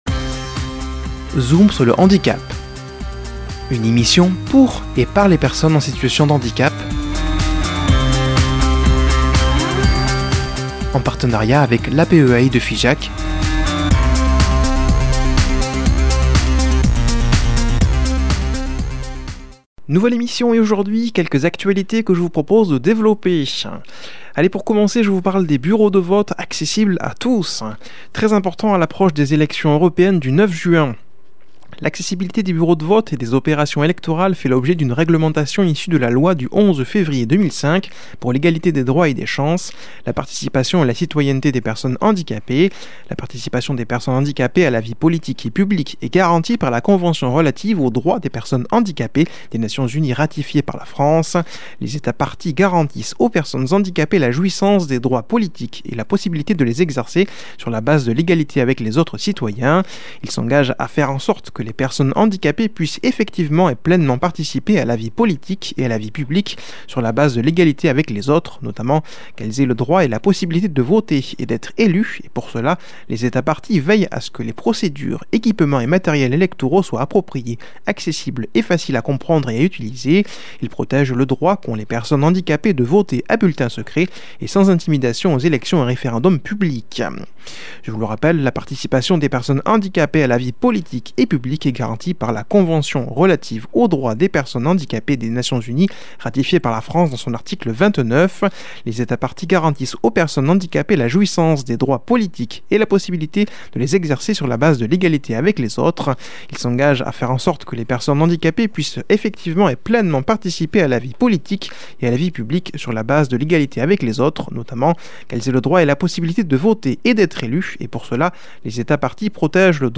Emission informative